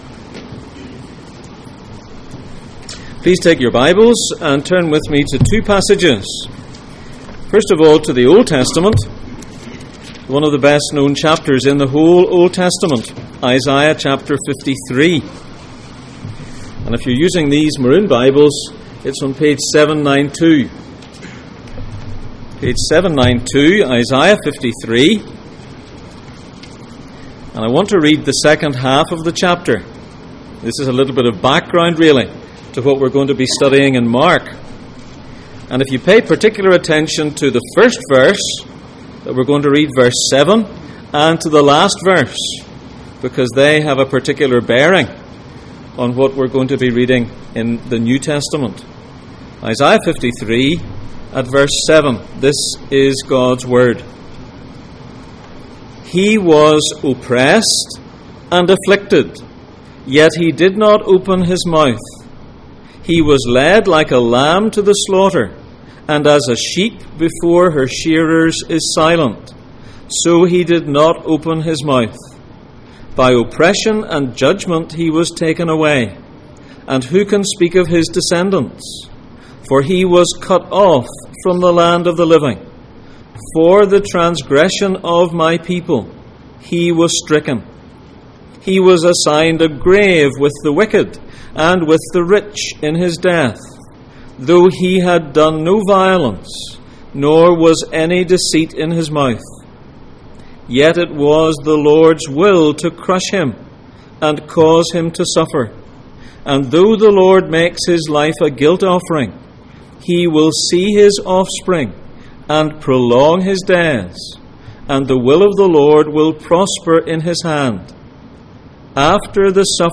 Jesus in Mark Passage: Mark 15:1-15, Isaiah 53:7-12, Luke 23:2, Matthew 20:18-19 Service Type: Sunday Morning